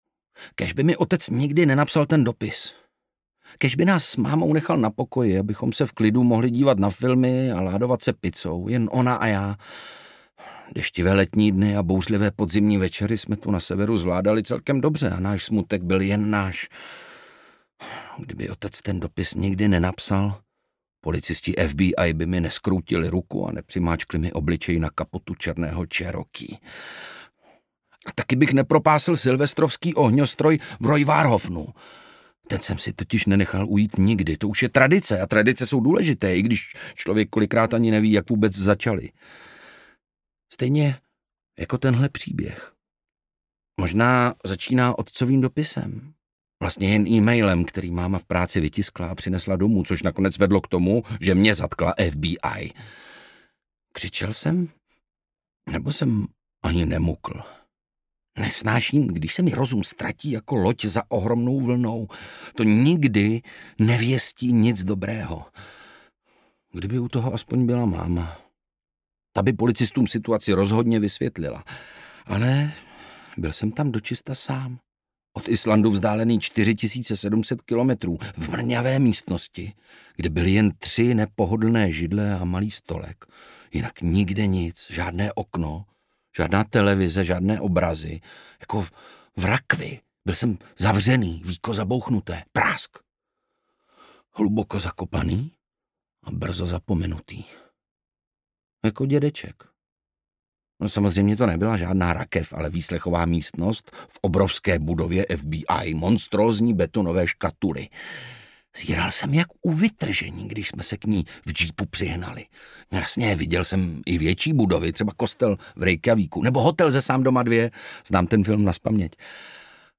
Audiobook
Read: Ondřej Brousek